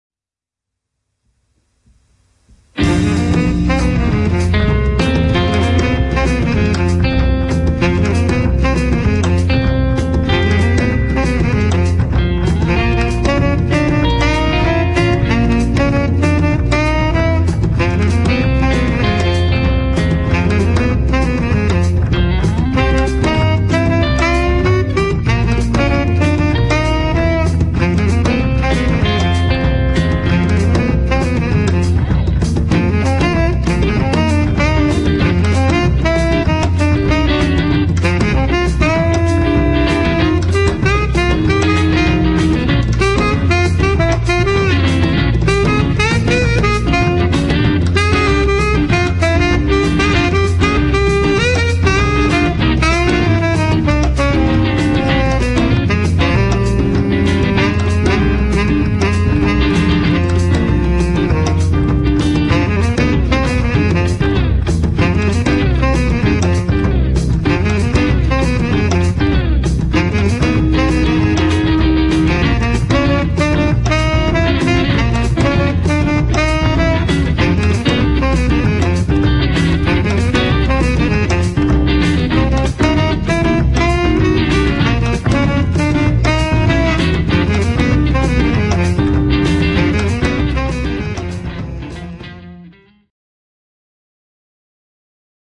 ジャズ、ジャイヴ、スウィング、ボサノヴァといったスタイルに敬意を払いつつも、結果的にはロックなサウンドに仕上がっている。